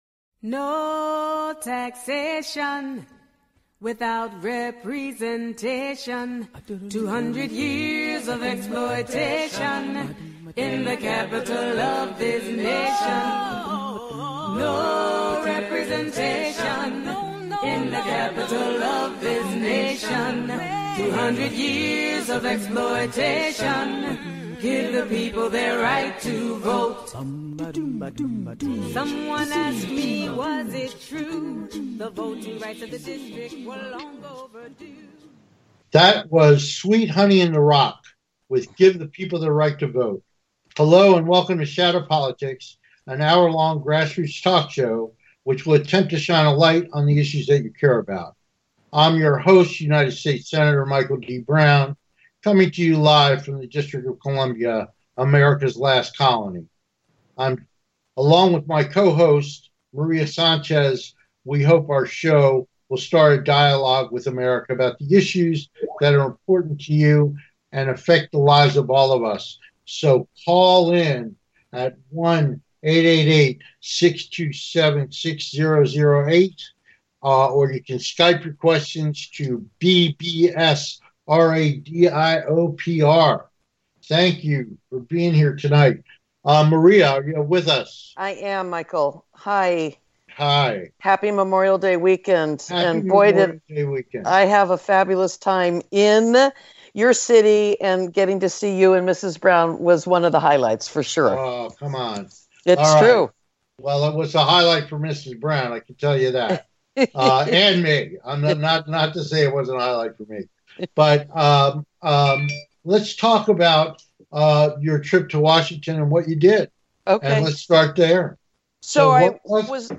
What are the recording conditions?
We look forward to having you be part of the discussion so call in and join the conversation.